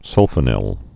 (sŭlfə-nĭl)